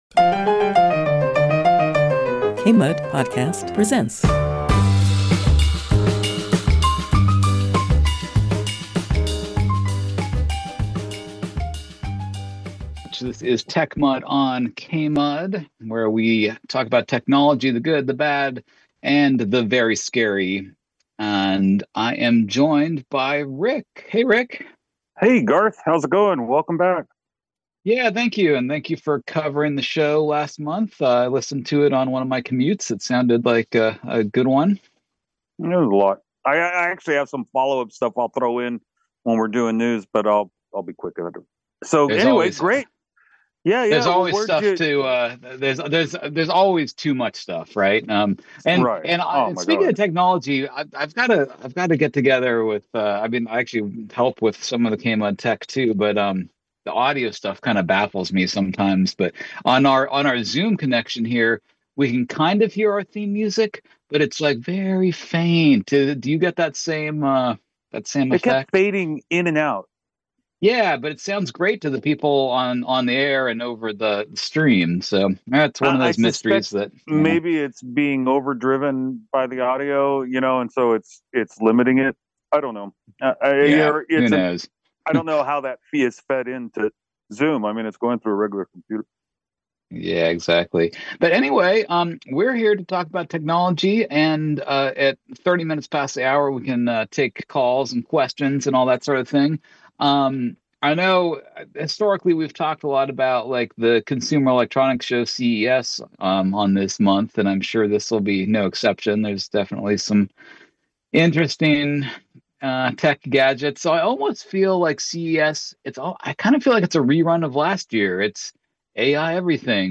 They also explore corporate news stories and how they often appear identical. Throughout the show, they take calls to answer listener questions and debate conspiracy theories, such as lasers from space.